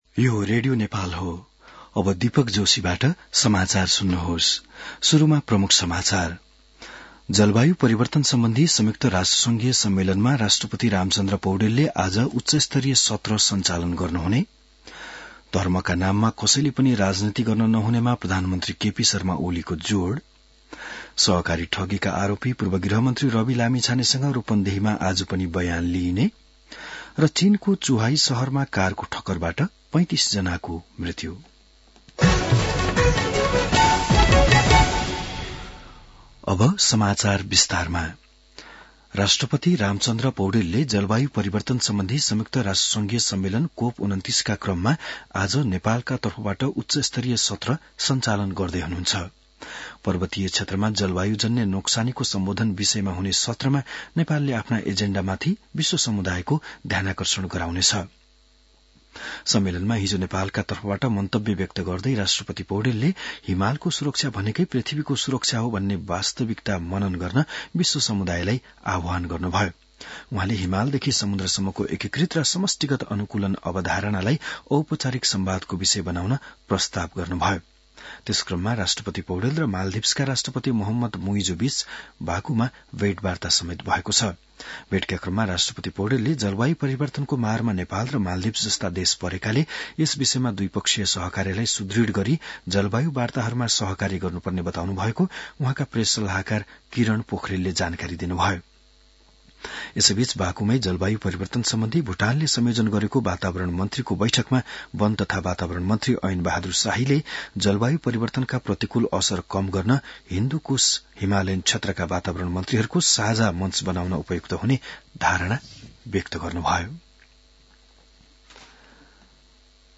बिहान ९ बजेको नेपाली समाचार : २९ कार्तिक , २०८१